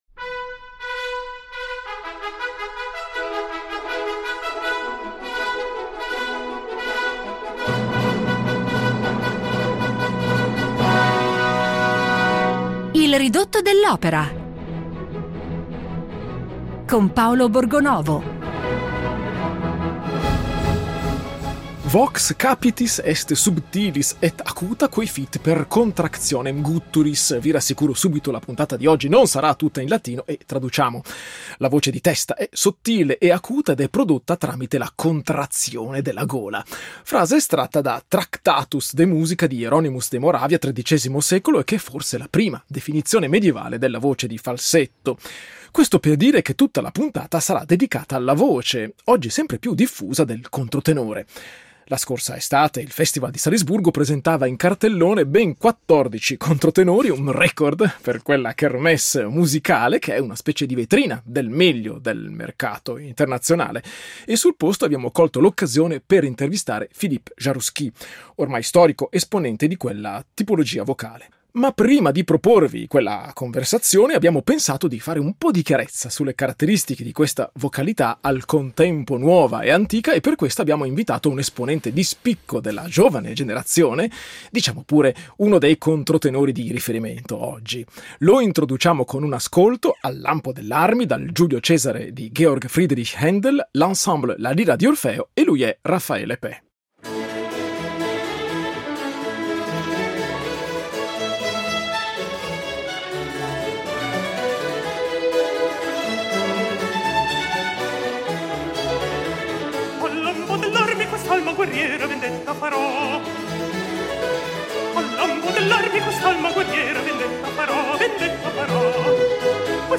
Puntata speciale